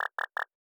Knock Notification 2.wav